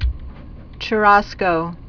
Pronunciation: